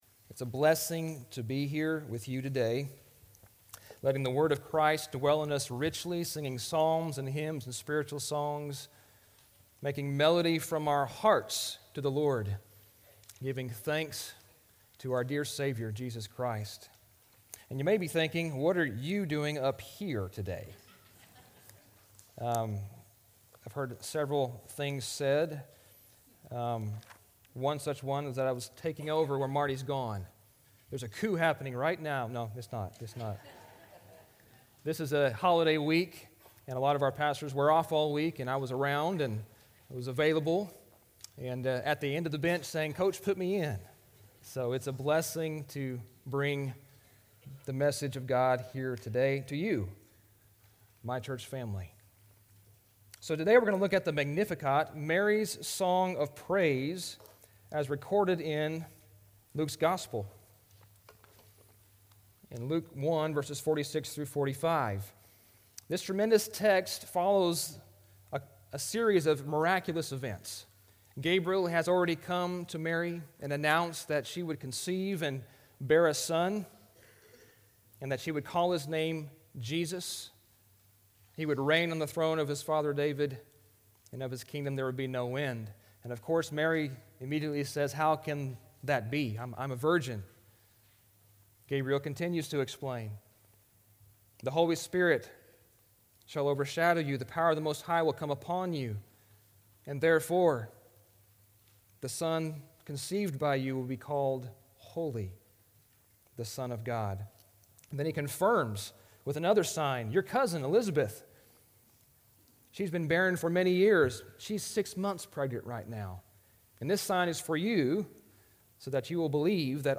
12-2-24-Sermon-Audio.mp3